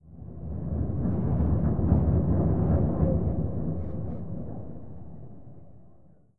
Commotion8.ogg